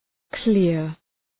Προφορά
{klıər}